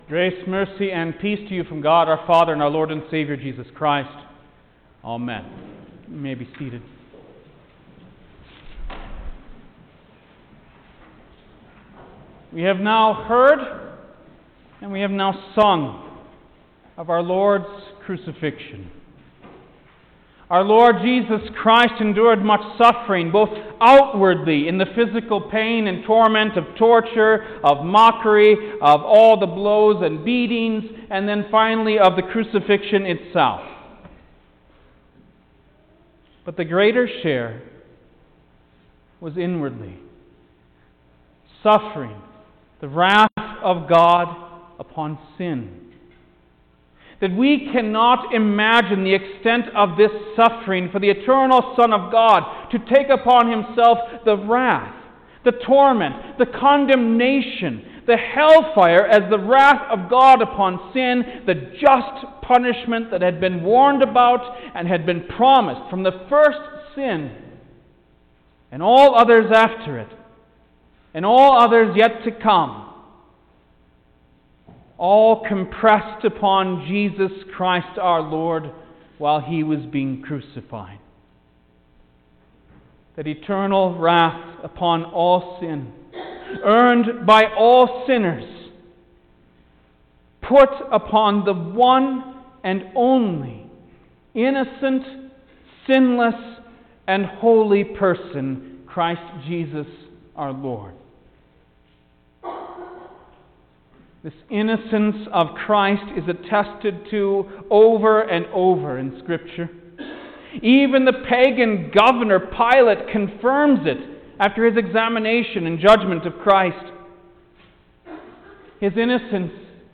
April-2_2021_Good-Friday-Service_Sermon-mono.mp3